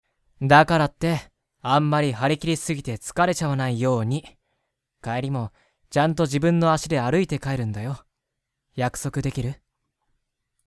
・真面目で勤勉、大人しい少年だった。
【サンプルボイス】